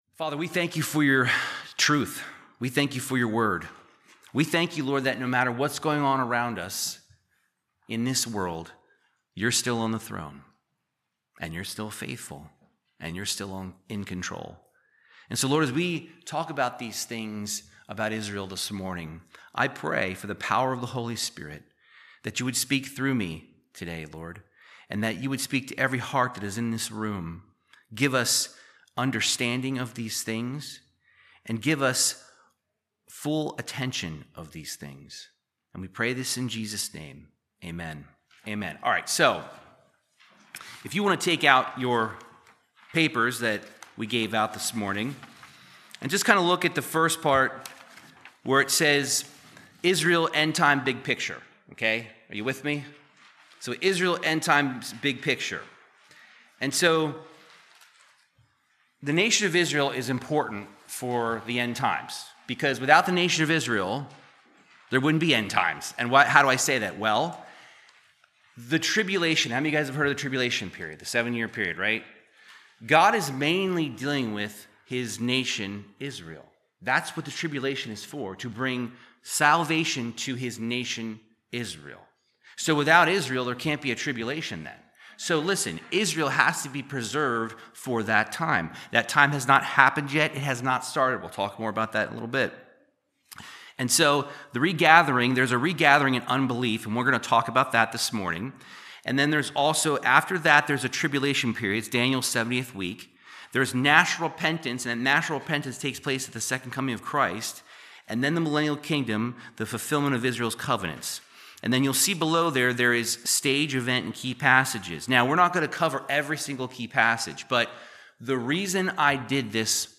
Teaching on Bible Prophecy and the End Times discussing the plans for Israel and the Church